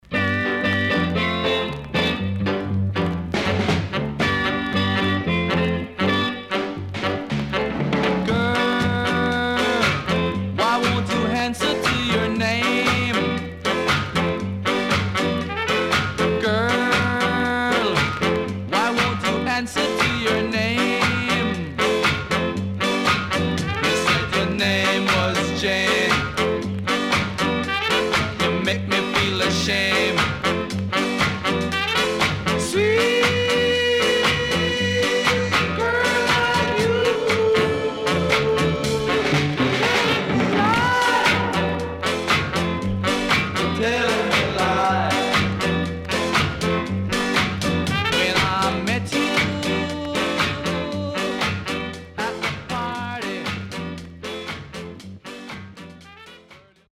ROCKSTEADY
SIDE A:所々チリノイズ、プチノイズ入ります。